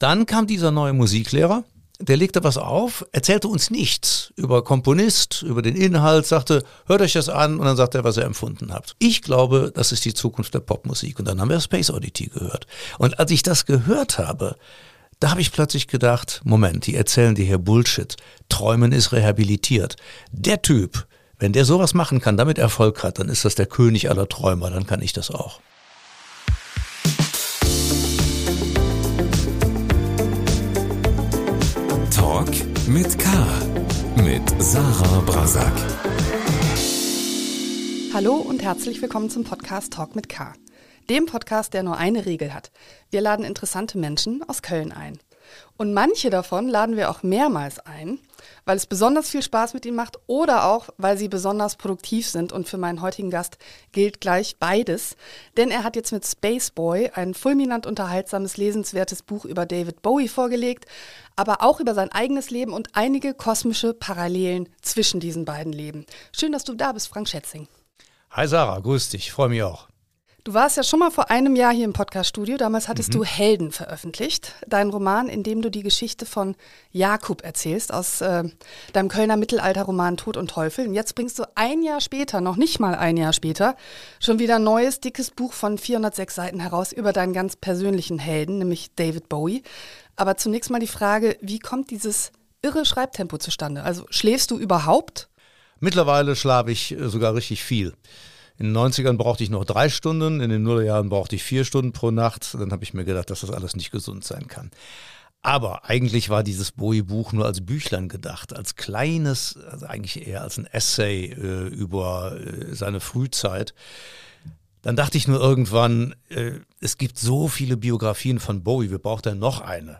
Was war der peinlichste Moment Ihres Lebens, Frank Schätzing? Der Kölner Bestseller-Autor im Gespräch ~ Talk mit K - der Talk-Podcast des Kölner Stadt-Anzeiger Podcast